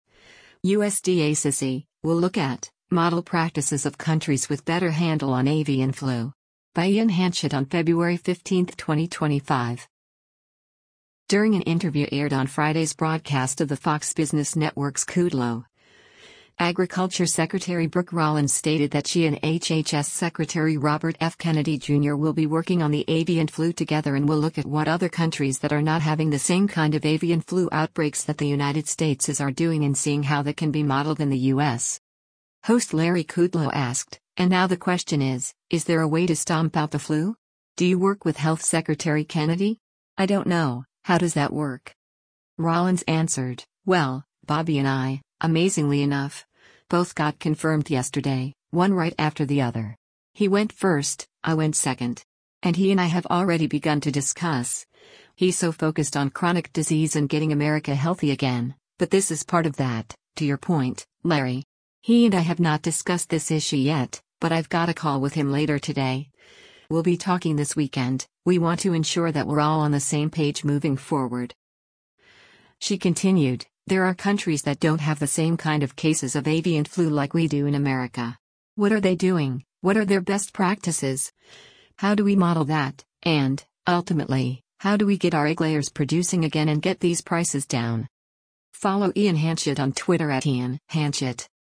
During an interview aired on Friday’s broadcast of the Fox Business Network’s “Kudlow,” Agriculture Secretary Brooke Rollins stated that she and HHS Secretary Robert F. Kennedy Jr. will be working on the avian flu together and will look at what other countries that are not having the same kind of avian flu outbreaks that the United States is are doing and seeing how that can be modeled in the U.S.